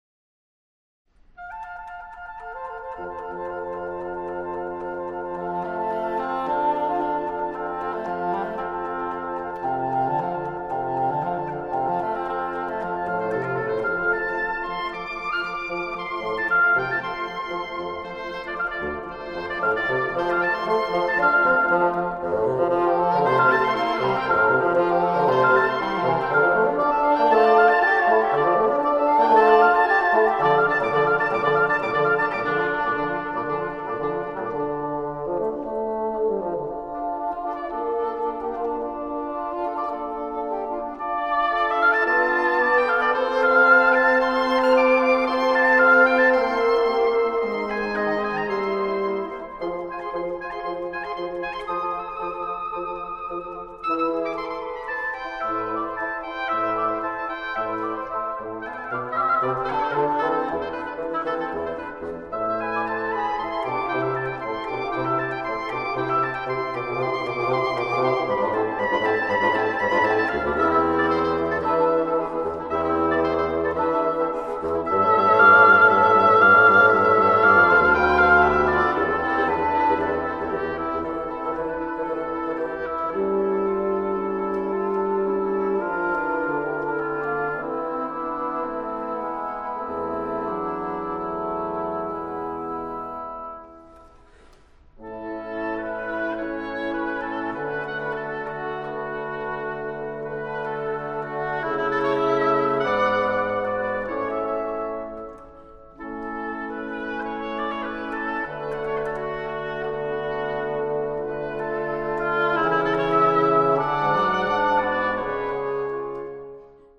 Allegro ma non troppo